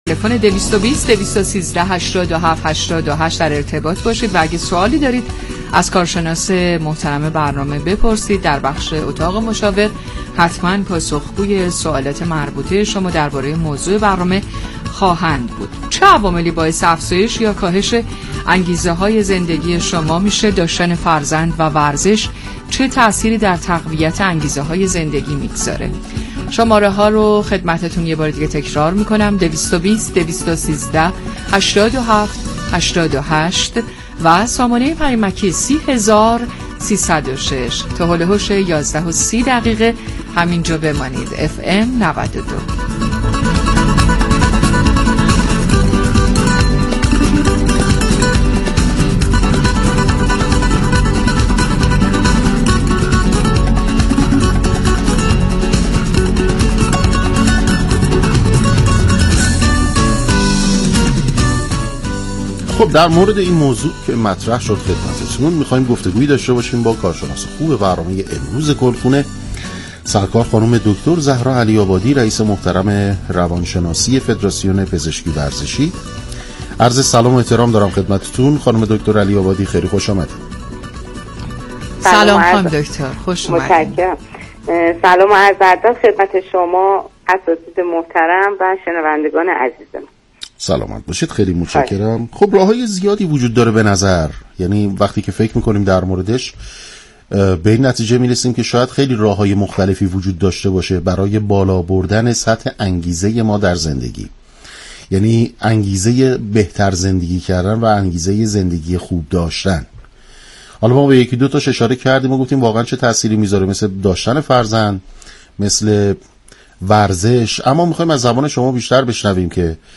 /گفتگوی رادیویی/